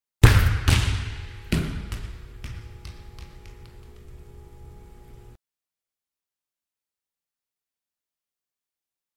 Звуки волейбола
Мощный удар по мячу, но промах